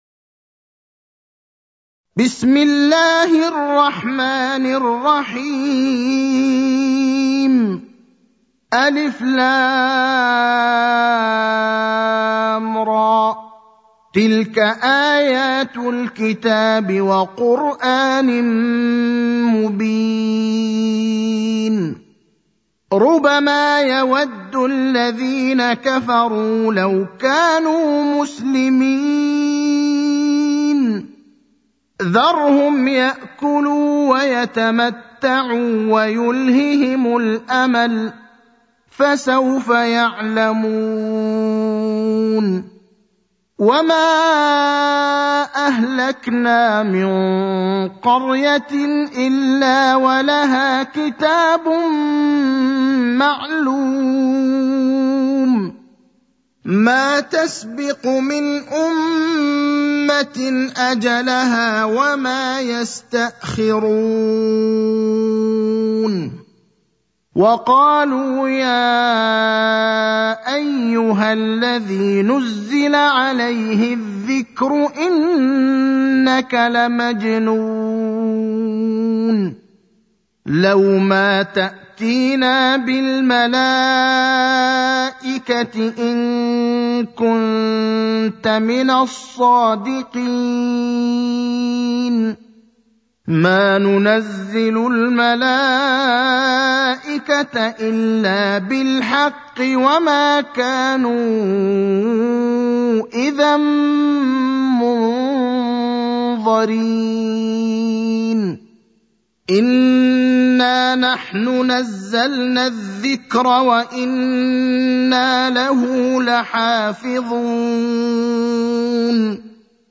المكان: المسجد النبوي الشيخ: فضيلة الشيخ إبراهيم الأخضر فضيلة الشيخ إبراهيم الأخضر الحجر (15)